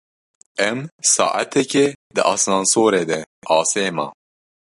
Pronunciado como (IPA)
/ɑːˈseː/